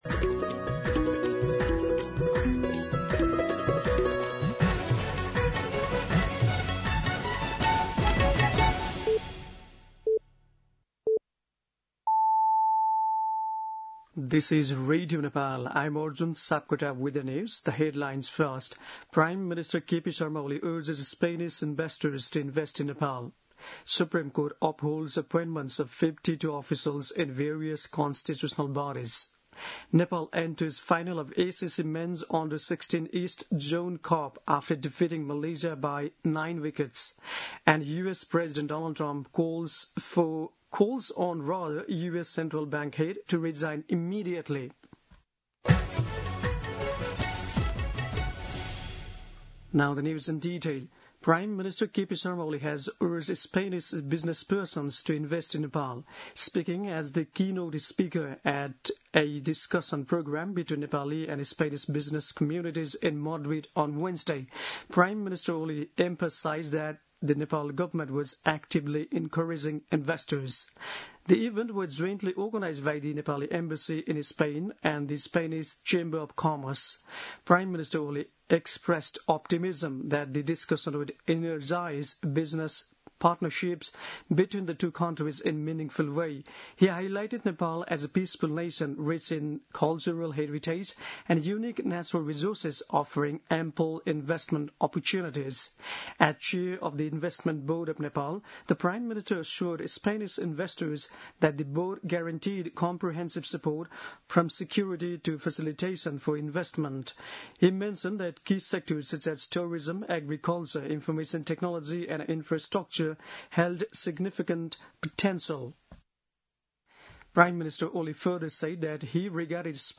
दिउँसो २ बजेको अङ्ग्रेजी समाचार : १९ असार , २०८२
2-pm-English-News-03-19.mp3